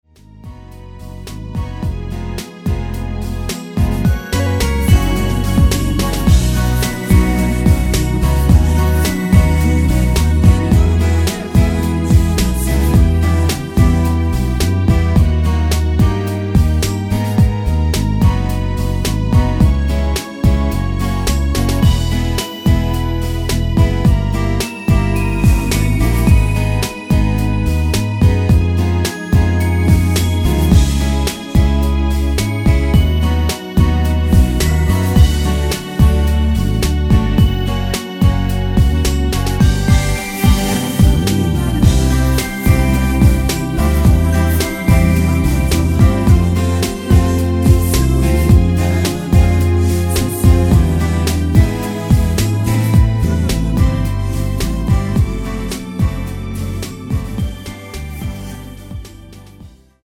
원키 코러스 포함된 MR 입니다.
앞부분30초, 뒷부분30초씩 편집해서 올려 드리고 있습니다.
중간에 음이 끈어지고 다시 나오는 이유는